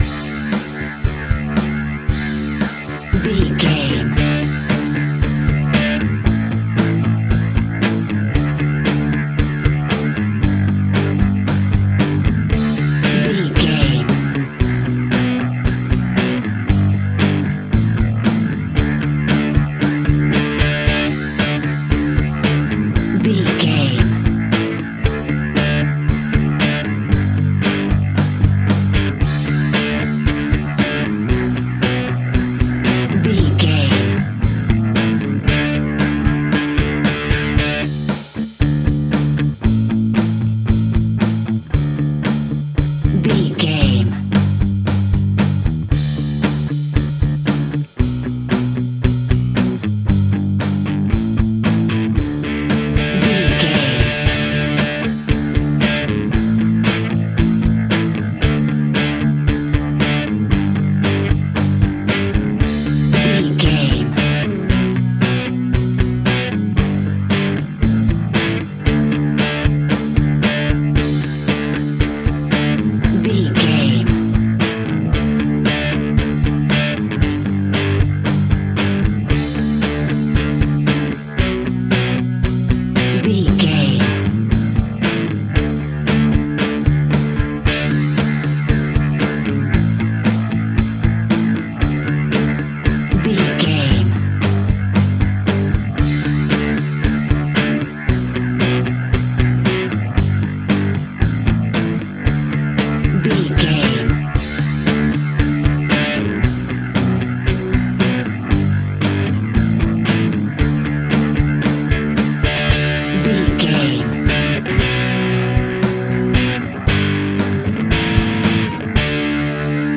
Ionian/Major
D
energetic
driving
heavy
aggressive
electric guitar
bass guitar
drums
heavy rock
distortion
Instrumental rock